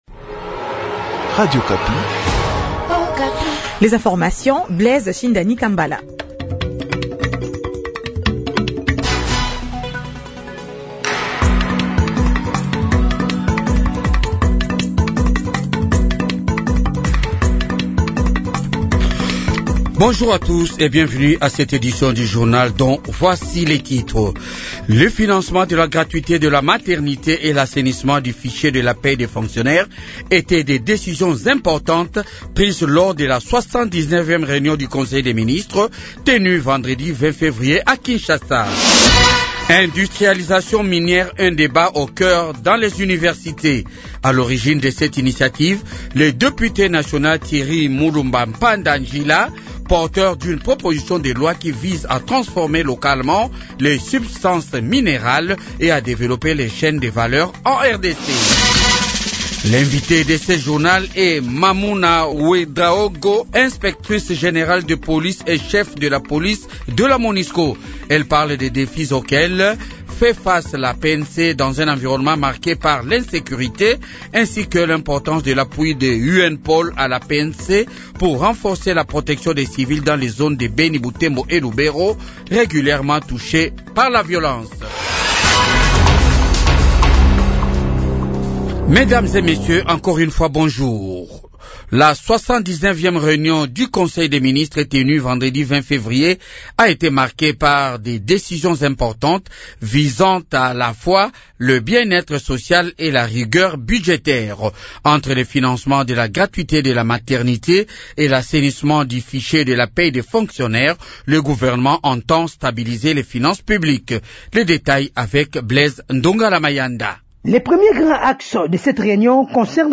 Journal du matin 8h